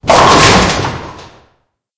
bowling-10.wav